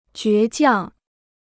倔强 (jué jiàng) Dictionnaire chinois gratuit